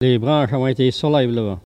Maraîchin
Catégorie Locution